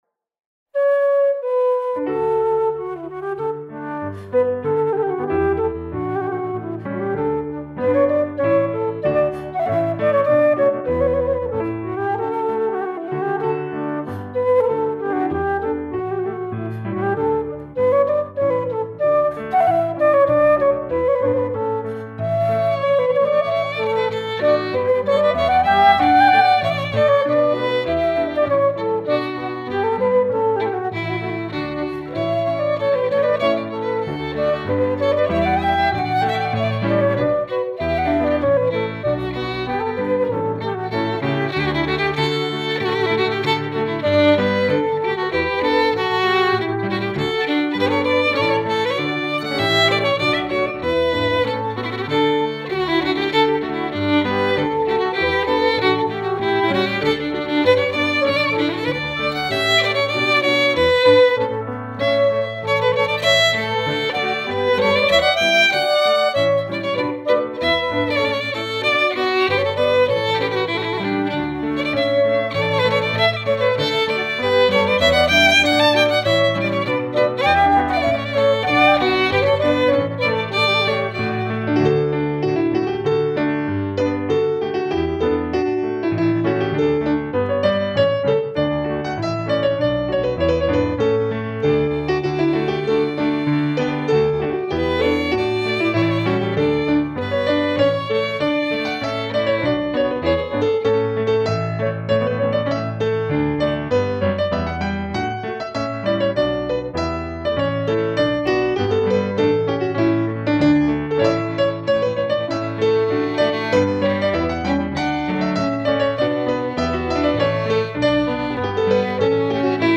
Structure as played: AAB